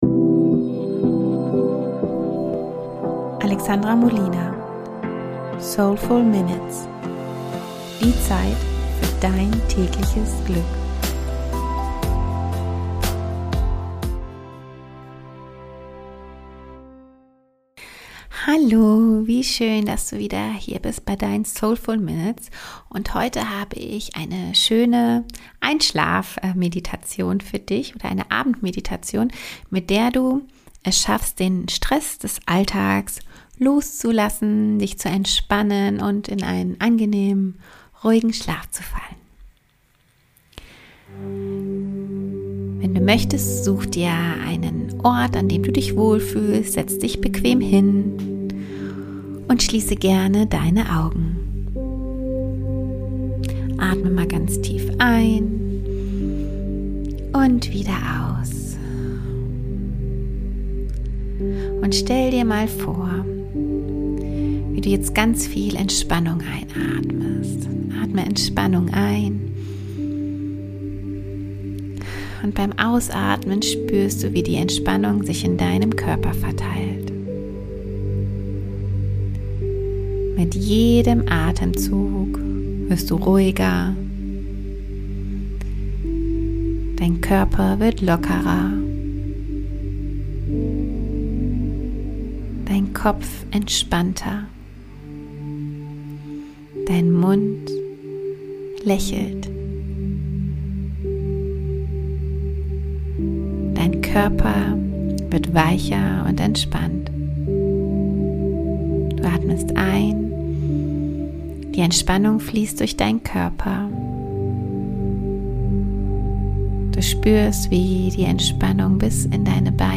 Abendmeditation - den Stress des Tages loslassen ~ Soulful Minutes - Zeit für dich Podcast